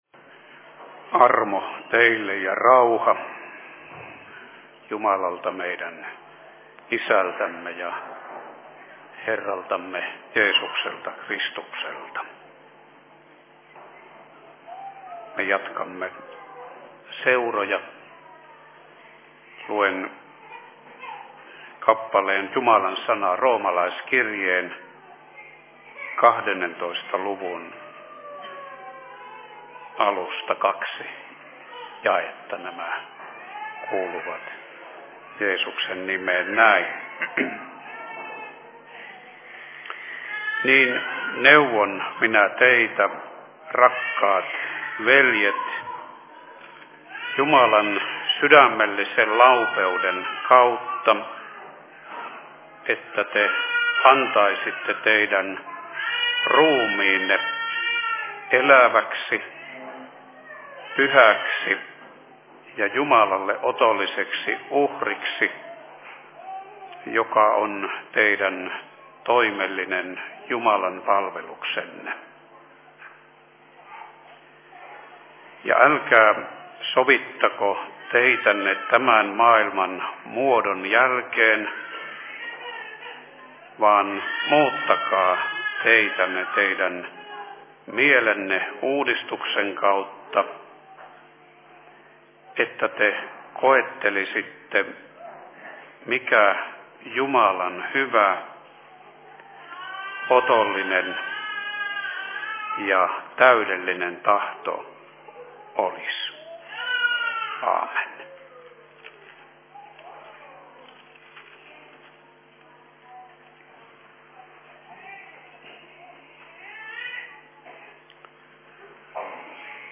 Seurapuhe 03.01.2010
Paikka: Rauhanyhdistys Tornio